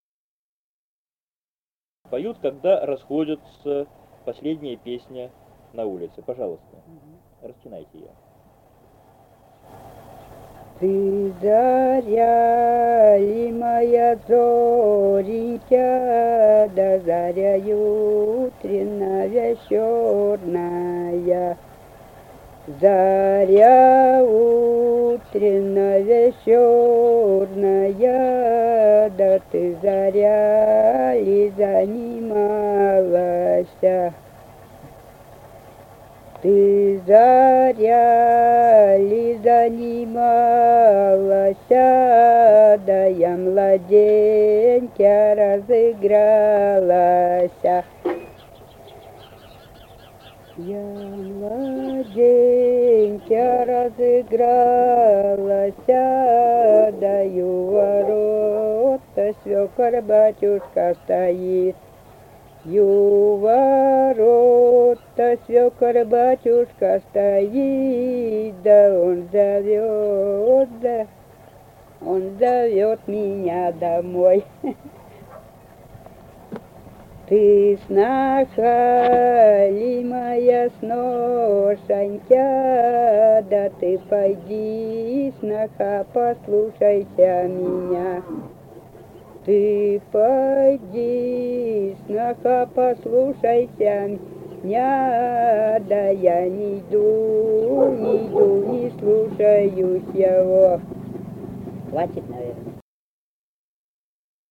Файл:27а Ты заря ли, моя зорюшка И1774-26 (solo) Белое.wav.mp3 — Фолк депозитарий
Русские песни Алтайского Беловодья 2 [[Описание файла::«Ты заря ли, моя зоренька», «лужошная»; поют, когда расходятся [с гуля- ния]. Последняя песня на улице).
Республика Казахстан, Восточно-Казахстанская обл., Катон-Карагайский р-н с. [Белое].